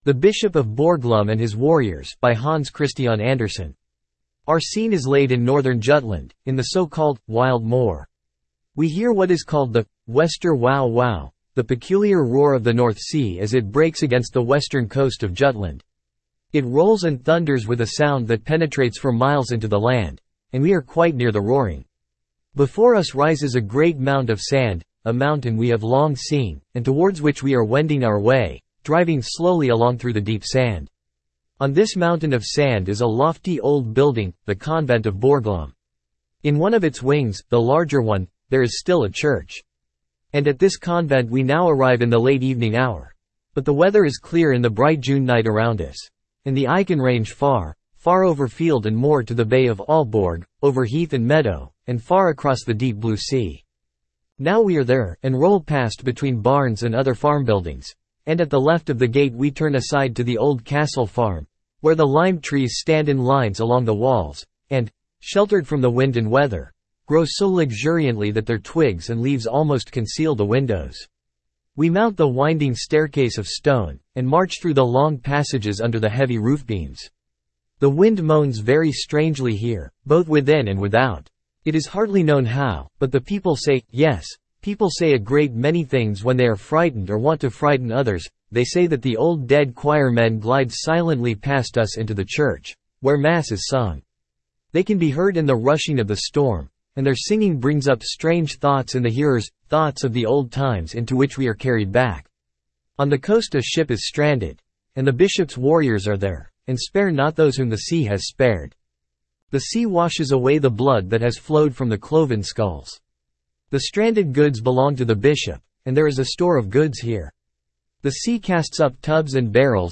Spoken Ink « Fairy Tales of Hans Christian Andersen The Bishop Of Borglum And His Warriors Standard (Male) Download MP3 Our scene is laid in Northern Jutland, in the so-called "wild moor."
the-bishop-of-borglum-and-his-warriors-en-US-Standard-D-2a7be162.mp3